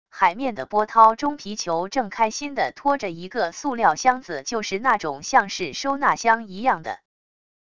海面的波涛中皮球正开心的拖着一个塑料箱子就是那种像是收纳箱一样的wav音频